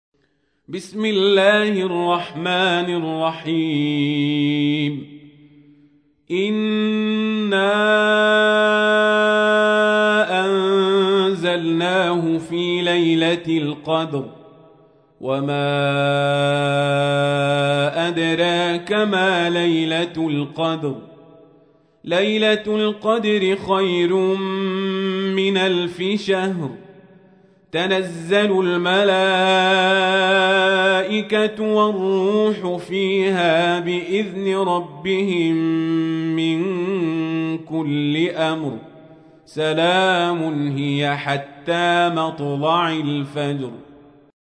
تحميل : 97. سورة القدر / القارئ القزابري / القرآن الكريم / موقع يا حسين